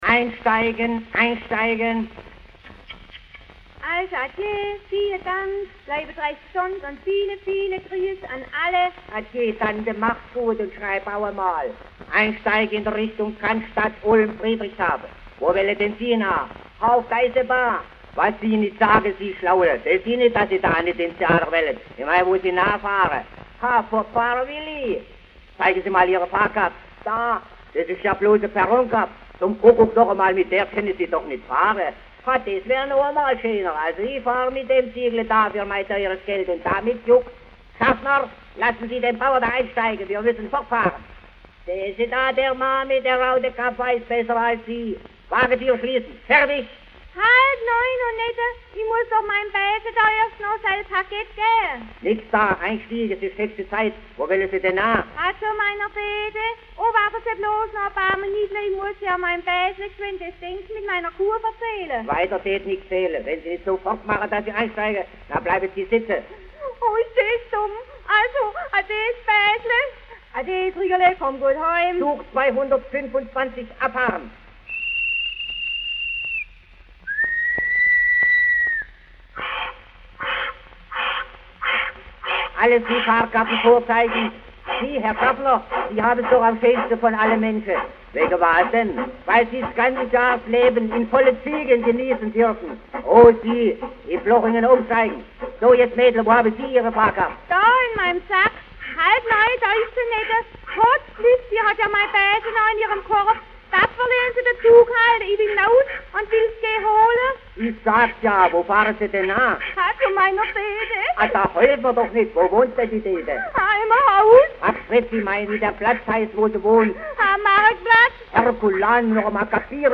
Schellackplattensammlung